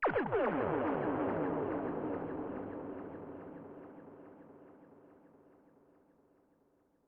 Death Ray.wav